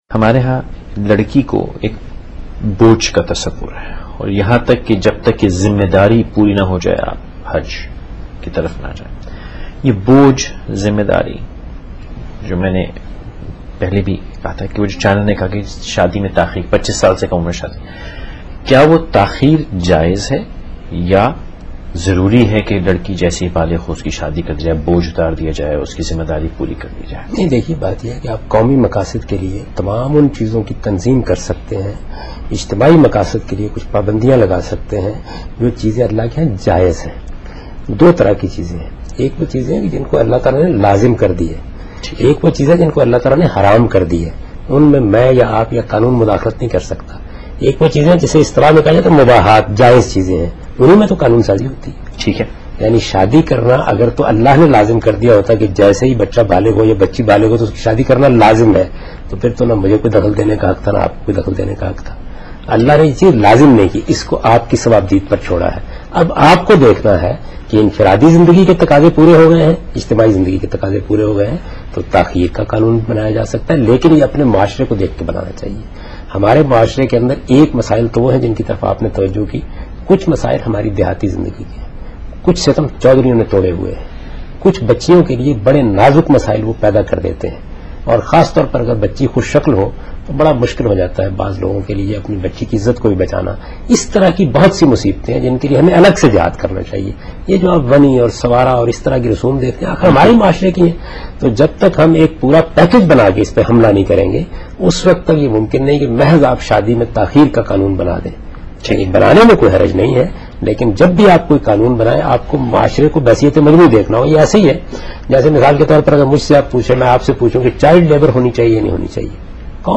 Category: TV Programs / Samaa Tv / Questions_Answers /
Javed Ahmad Ghamidi answers a question regarding "Burdon of a Daughter and Hajj" in Ankahi show on Samaa tv.
جاوید احمد غامدی سما ٹی وی کے پروگرام انکہی میں بیٹی کا بوجھ اور حج سے متعلق ایک سوال کا جواب دے رہے ہیں۔